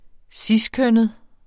Udtale [ ˈsis- ]